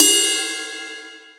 • Big Ride Cymbal Sound Clip F Key 05.wav
Royality free ride cymbal sound tuned to the F note.
big-ride-cymbal-sound-clip-f-key-05-cMk.wav